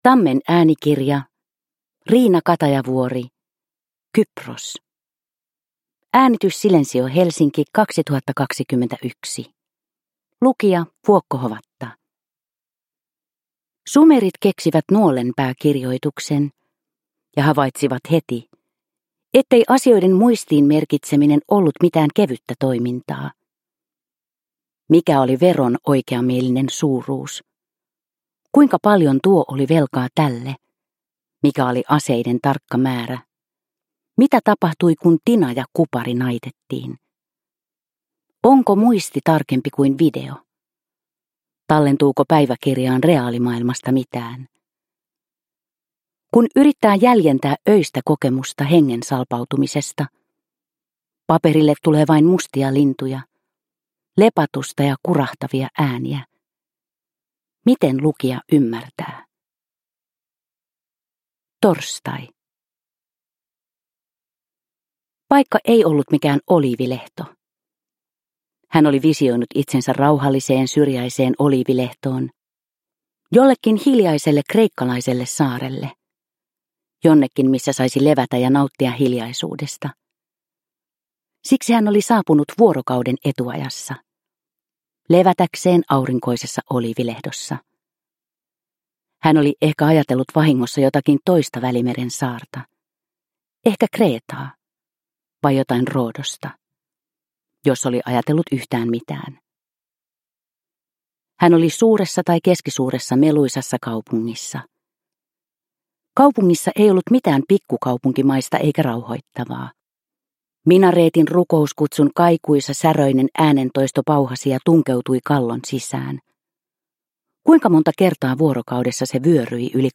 Kypros – Ljudbok – Laddas ner